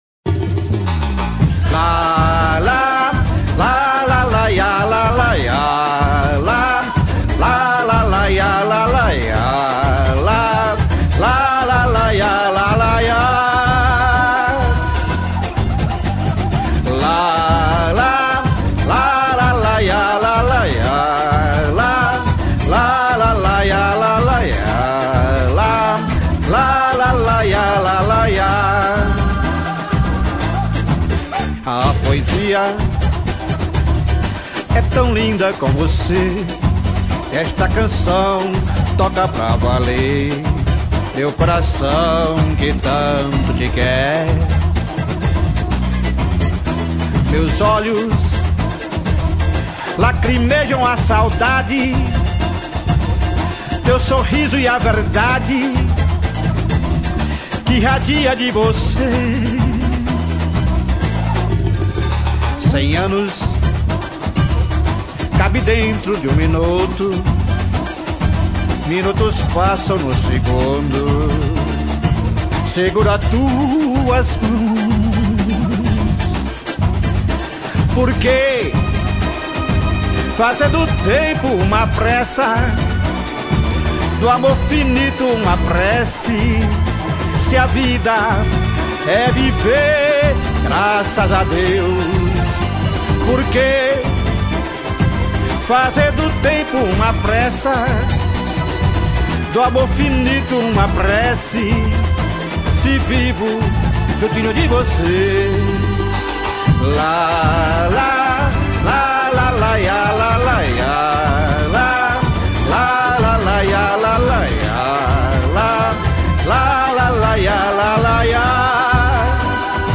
1551   04:19:00   Faixa:     Baião